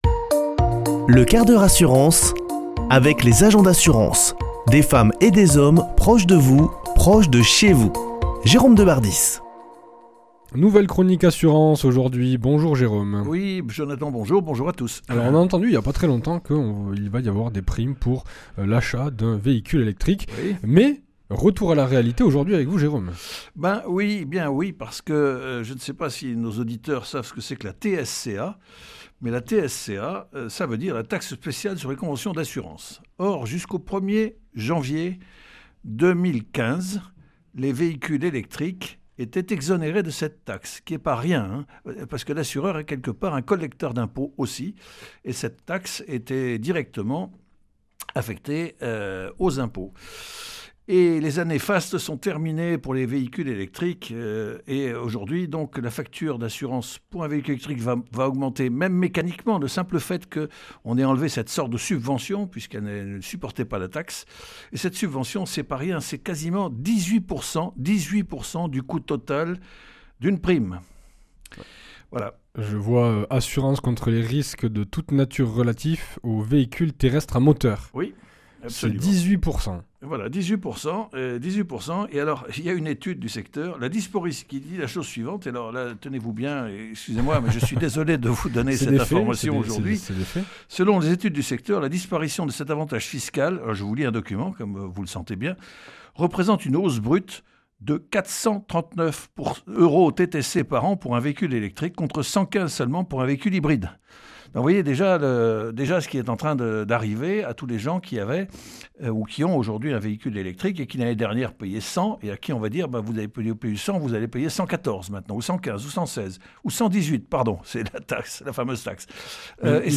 mardi 7 octobre 2025 Chronique le 1/4 h assurance Durée 5 min